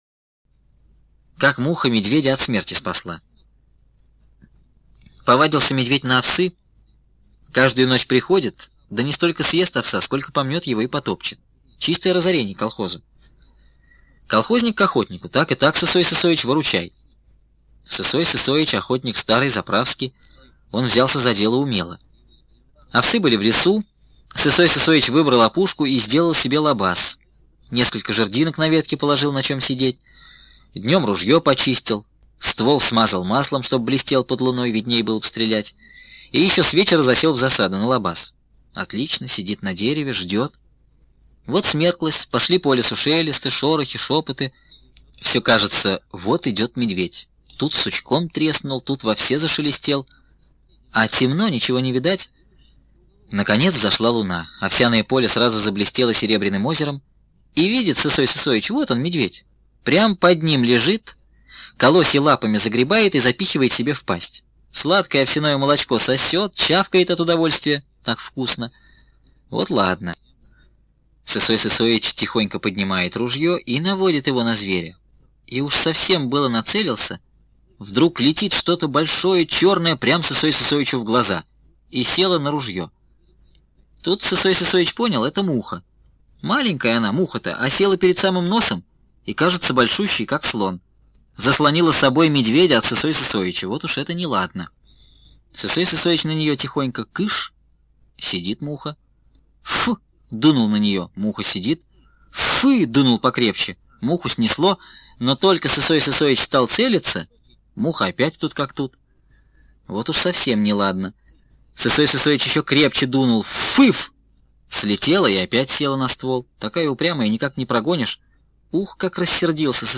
Как муха медведя от смерти спасла – Бианки В.В. (аудиоверсия)
Робот бот говорит видимо да еще и шепелявый.